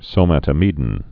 (sō-mătə-mēdn, sōmə-tə-)